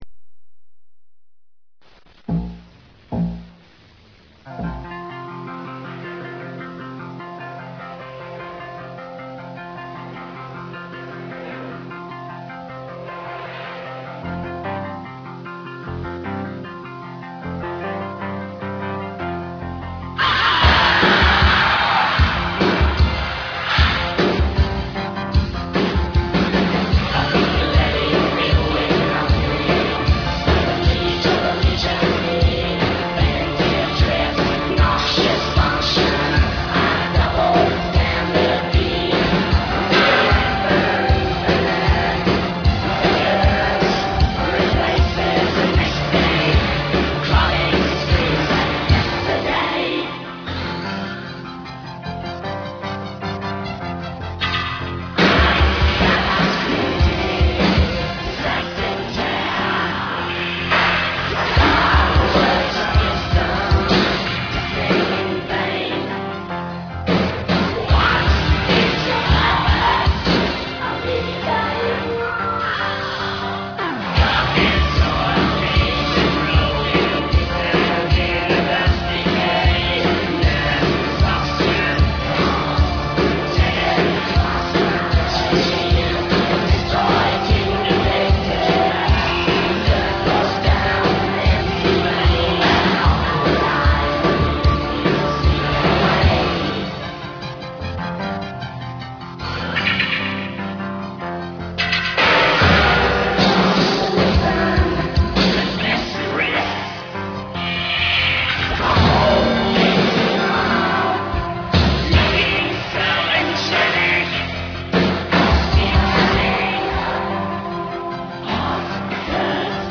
Recorded at Mushroom Studios 1985 • 1986